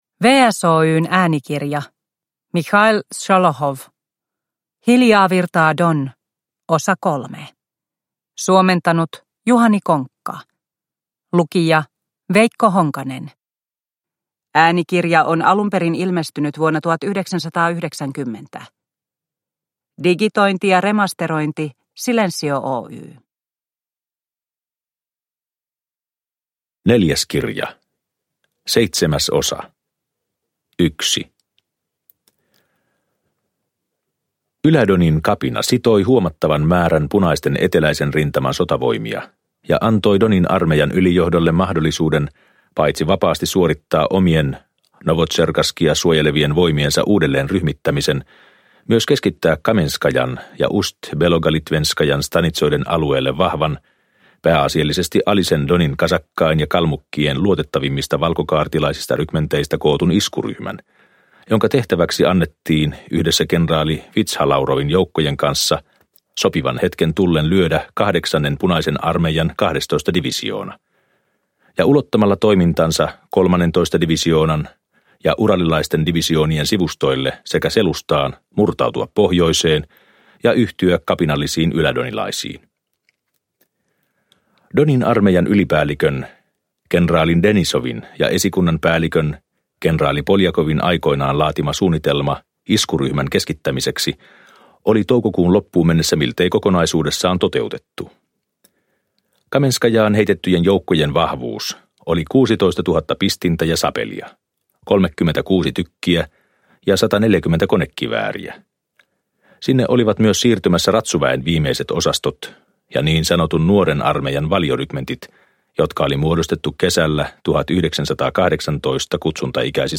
Hiljaa virtaa Don III – Ljudbok – Laddas ner